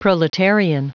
Prononciation du mot proletarian en anglais (fichier audio)
Prononciation du mot : proletarian